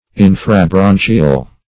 Search Result for " infrabranchial" : The Collaborative International Dictionary of English v.0.48: Infrabranchial \In`fra*bran"chi*al\, a. [Infra + branchial.]
infrabranchial.mp3